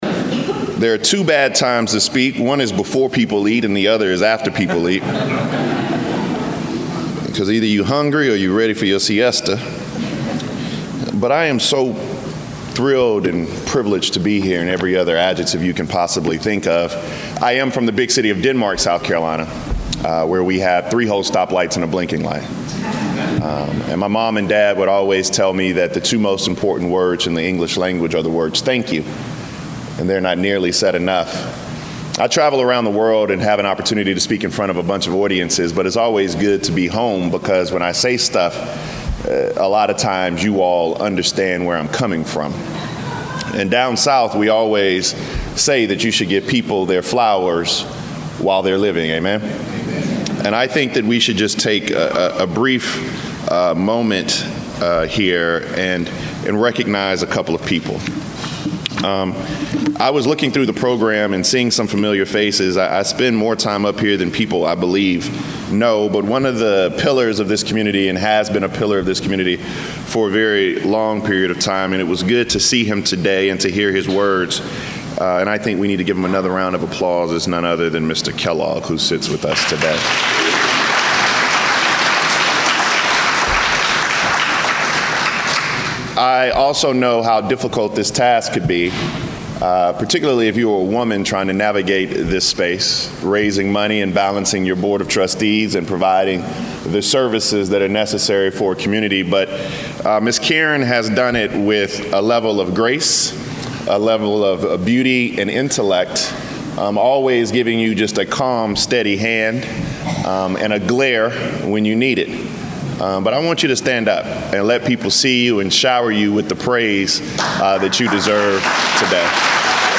AUDIO: Full speech from New York Times best seller Bakari Sellers from the 60th anniversary celebration of the CCA
Thursday was the 60th anniversary celebration of Carolina Community Actions. Bakari Sellers was the Keynote speaker who had the theme of “Where do we go from here”